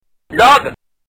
Dog
Category: Sound FX   Right: Personal